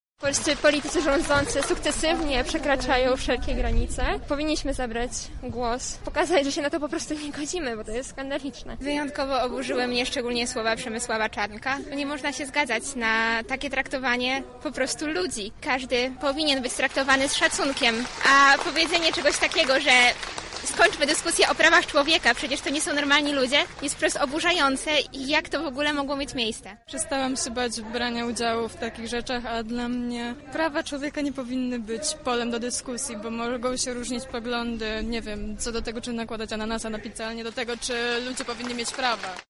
O swoich motywacjach dotyczących udziału w proteście mówili uczestnicy:
Protest LGBT